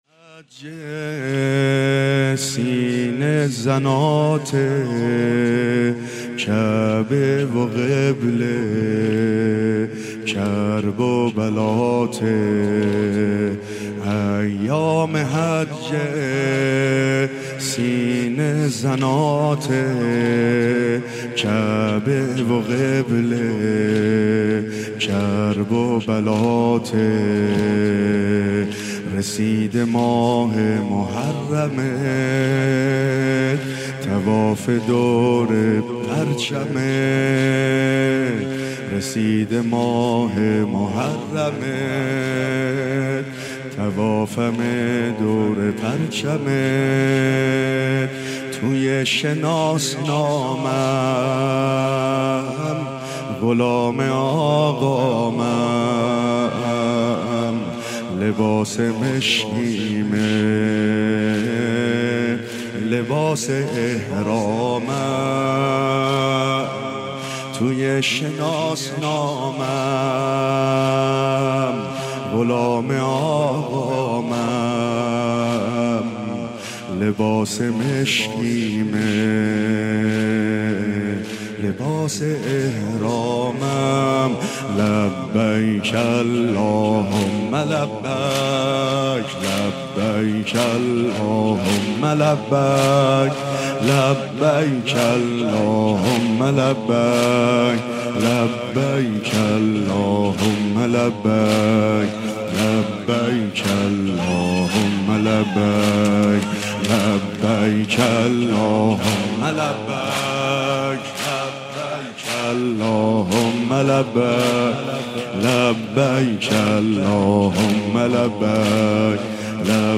مداحی های شب اول محرم هیت الرضا(ع)
مناجات/آهی کشید و گریه ما را درآورید
روضه/دلم یه کربلا می خواد